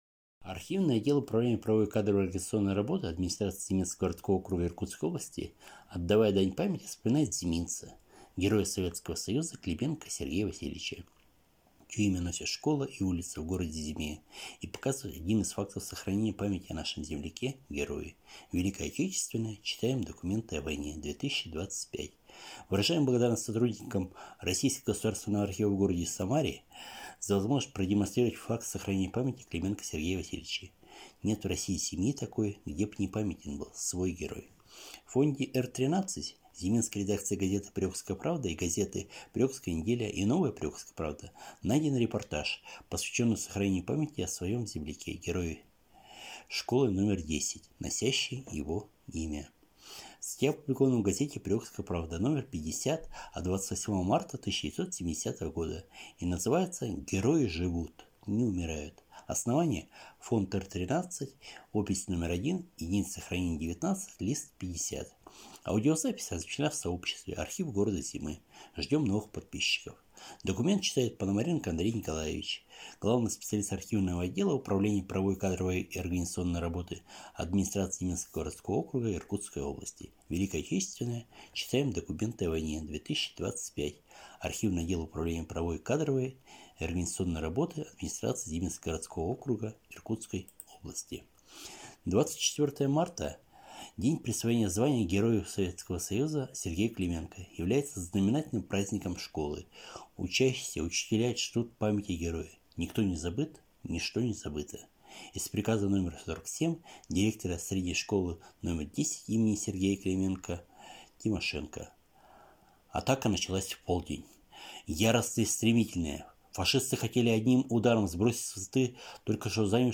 Читаем документы о войне - 2025